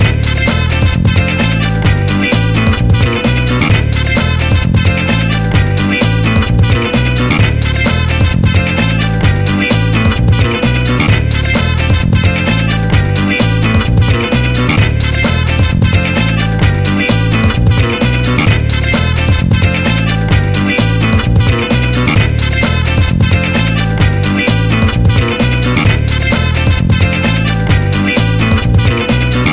Extracted from game files